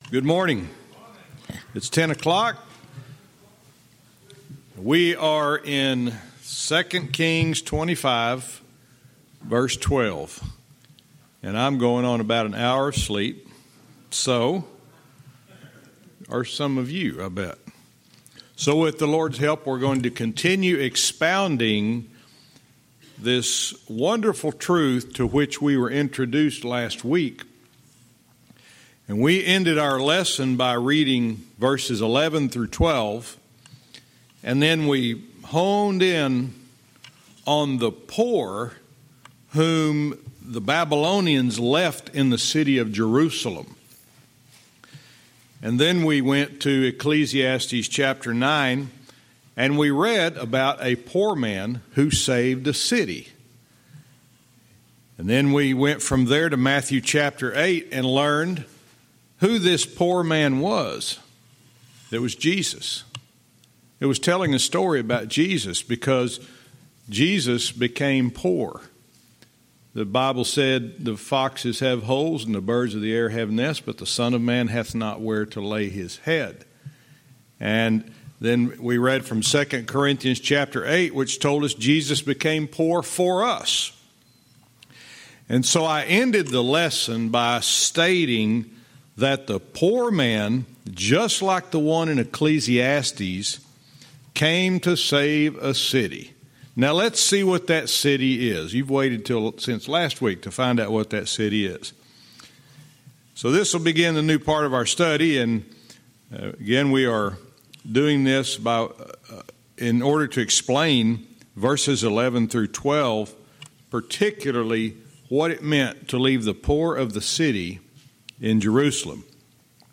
Verse by verse teaching - 2 Kings 25:13-16